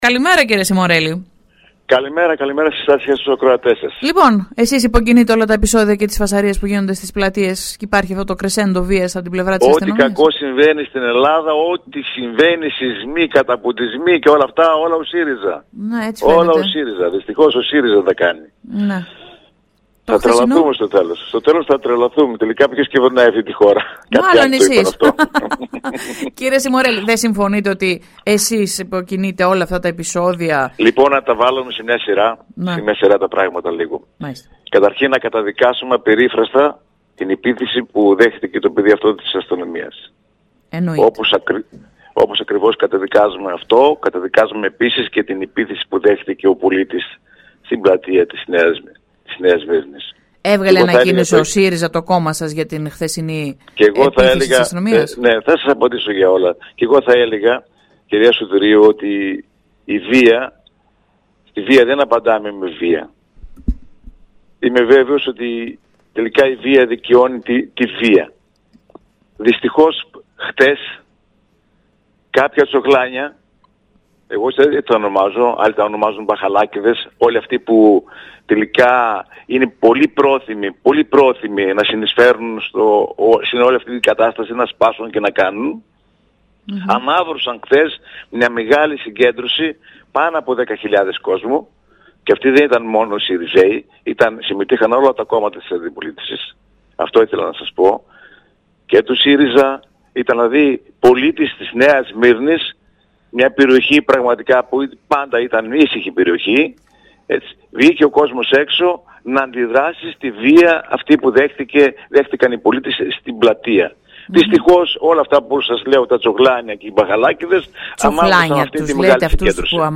Συνέντευξη στην Ραδιοφωνική Λέσχη 97,6 έδωσε σήμερα το πρωί ο πρώην Βουλευτής Τρικάλων του ΣΥΡΙΖΑ Χρήστος Σιμορέλης, αναφορικά με τα περιστατικά ωμής βίας που παρατηρήθηκαν τις τελευταίες μέρες στην πλατεία της Νέας Σμύρνης, μεταξύ αστυνομικών και πολιτών.